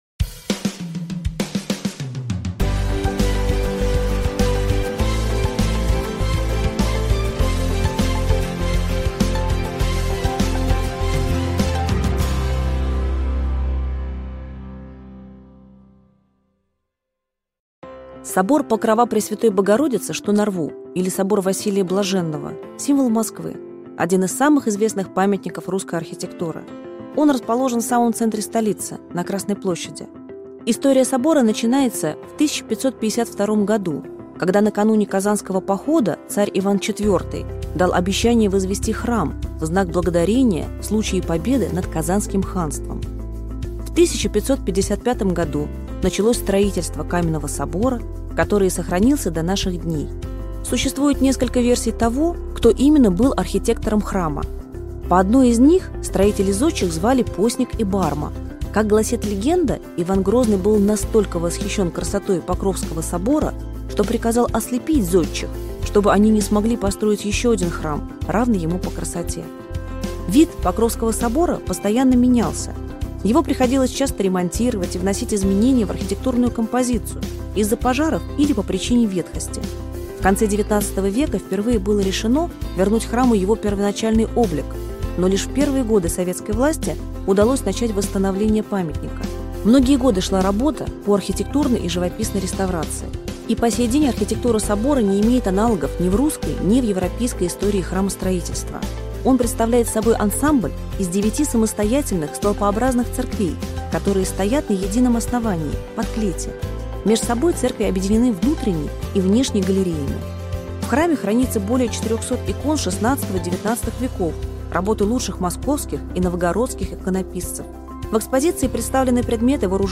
Аудиокнига Почему на храме купол? Два тысячелетия истории и символики купола | Библиотека аудиокниг